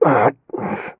m_pain_10.ogg